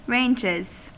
Ääntäminen
Ääntäminen US Haettu sana löytyi näillä lähdekielillä: englanti Käännöksiä ei löytynyt valitulle kohdekielelle. Ranges on sanan range monikko.